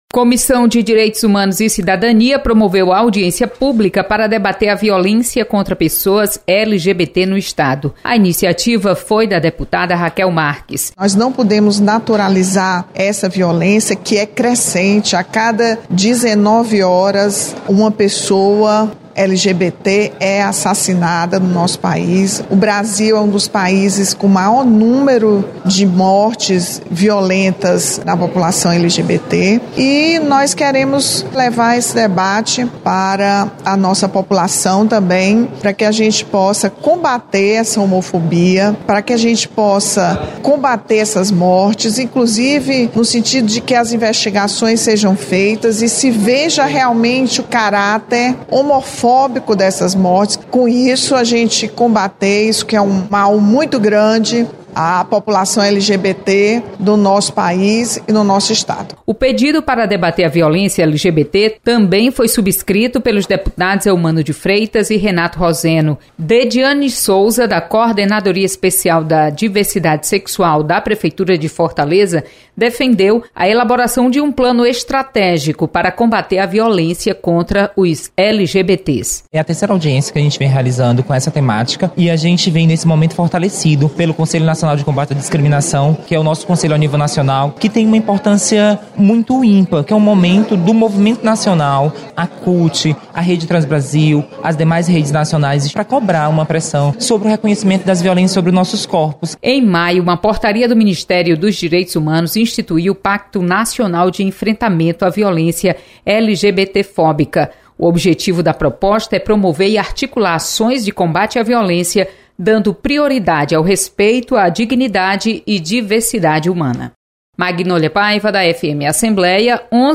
Acompanhe resumo das comissões técnicas permanentes da Assembleia. Repórter